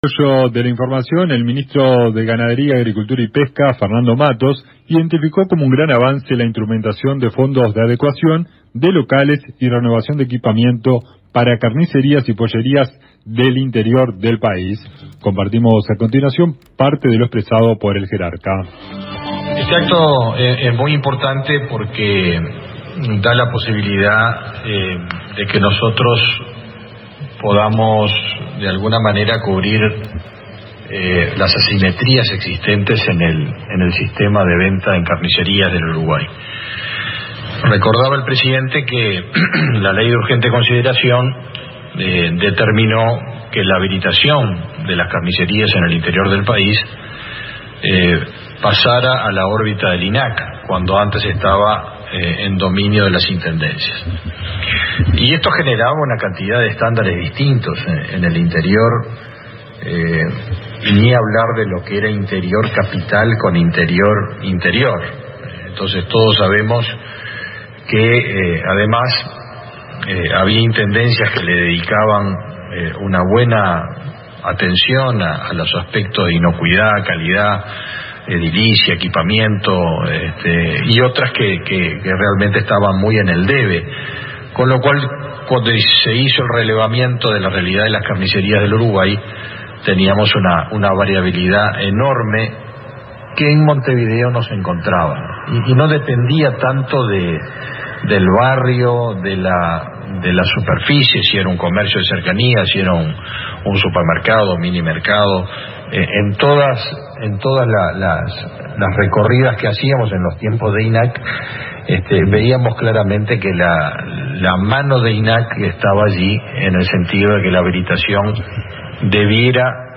Así lo informó el Ministro Fernando Mattos, reseñado por la AM 1110 de nuestra ciudad, quien agregó que ello permite cubrir las asimetrías que antes existían en el sistema de ventas de productos cárnicos a nivel nacional.
Escucha a Fernando Mattos aquí: